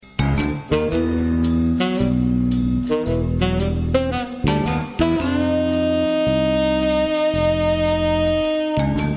Garage Synth